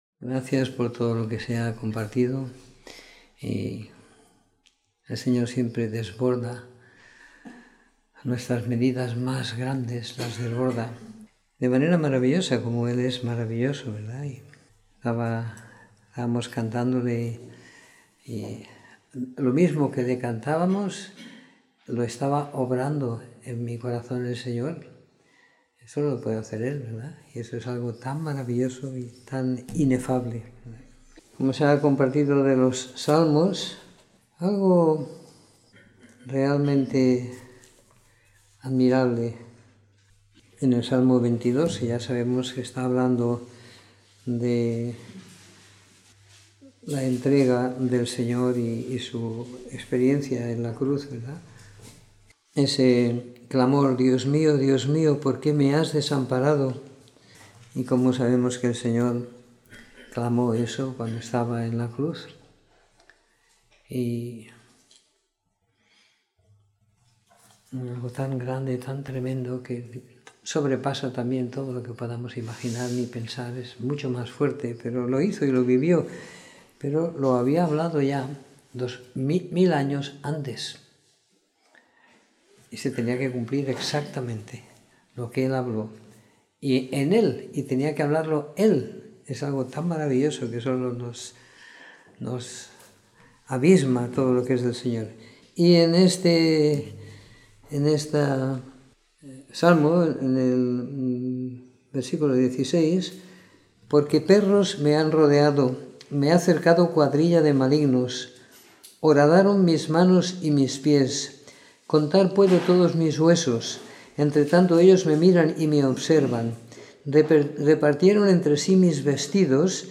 Comentario en el evangelio de Marcos siguiendo la lectura programada para cada semana del año que tenemos en la congregación en Sant Pere de Ribes.